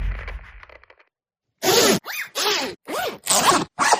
Robot Voice
Robot Voice is a free sci-fi sound effect available for download in MP3 format.
320_robot_voice.mp3